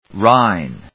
/rάɪn(米国英語)/